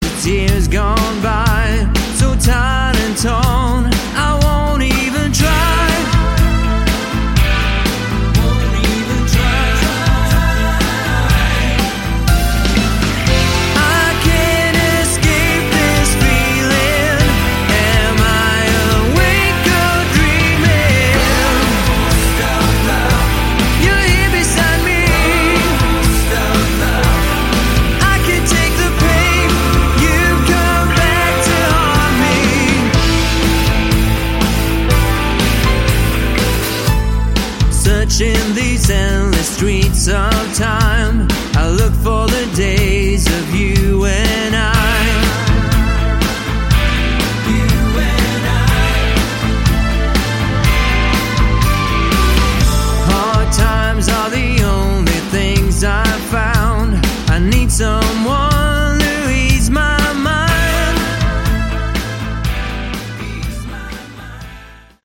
Category: AOR / Melodic Rock
Vocals, Guitars
Piano, Keyboards
Bass
Drums